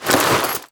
main Divergent / mods / 1st Person Visible Body / gamedata / sounds / gear_rustle / tac_gear_8.ogg 30 KiB (Stored with Git LFS) Raw Permalink History
tac_gear_8.ogg